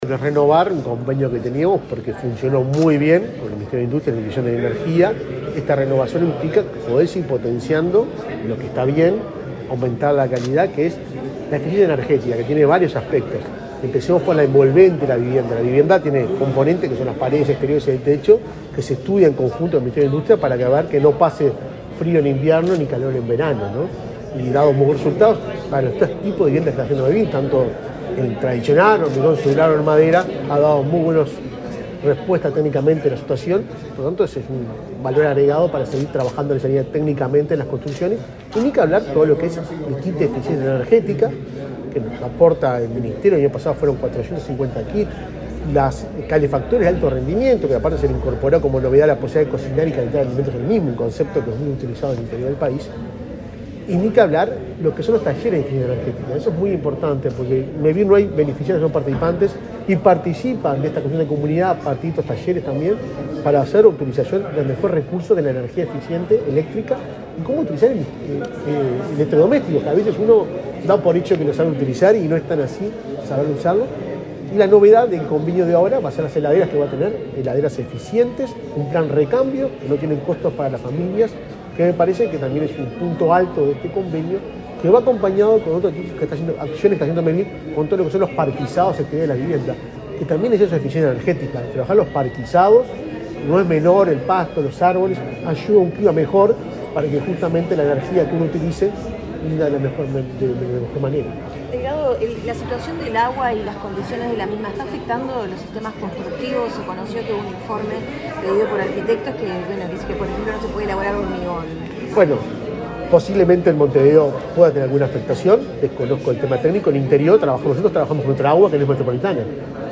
Declaraciones del presidente de Mevir, Juan Pablo Delgado
Declaraciones del presidente de Mevir, Juan Pablo Delgado 04/07/2023 Compartir Facebook X Copiar enlace WhatsApp LinkedIn El presidente de Mevir, Juan Pablo Delgado, dialogó con la prensa antes de participar en el acto de firma de un convenio con el Ministerio de Industria, Energía y Minería.